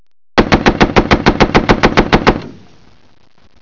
Light Navel Gunfire
Vickers 303 (a combination of short bursts of gun fire) -40 mm Pom Pom (a mixture of a long and short bursts - 20 mm Oerlikon (light Naval gun).